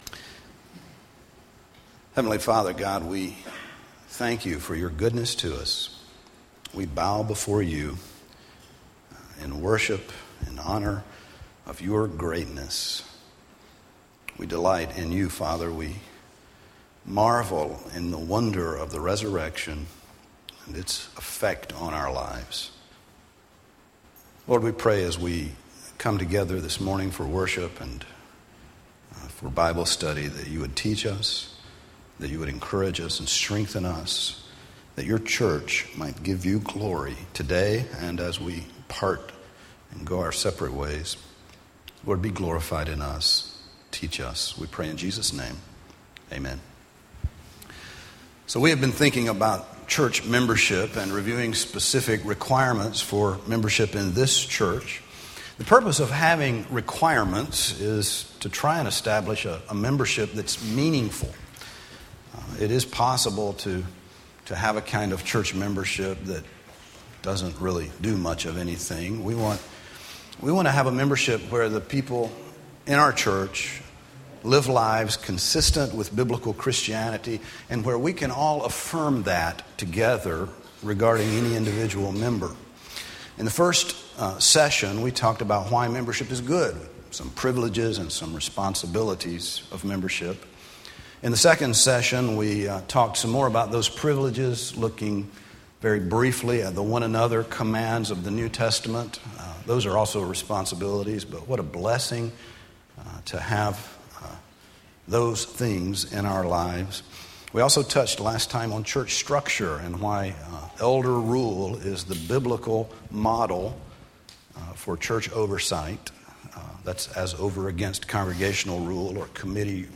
Church Membership Class, Session 3